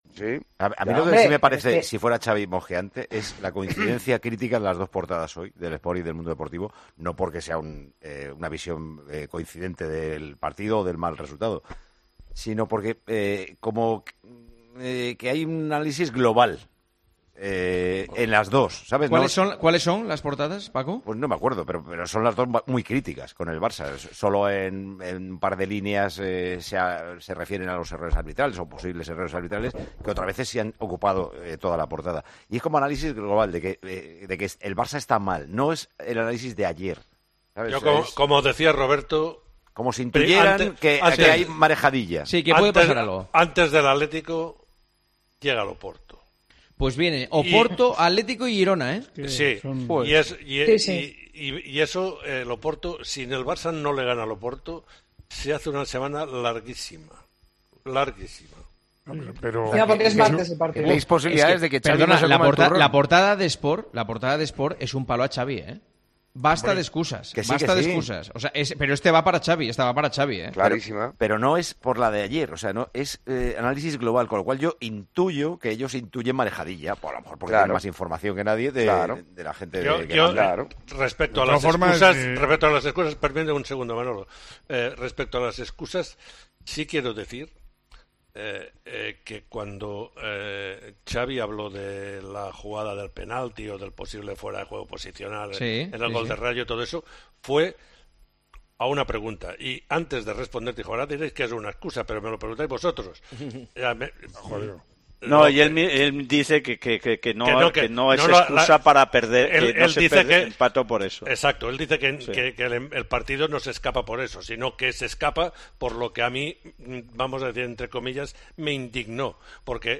El director y presentador de Tiempo de Juego, Paco González, analizó estas portadas durante la edición de anoche de El Tertulión de los domingos con Juanma Castaño y se puso además en el lugar del técnico Xavi Hernández.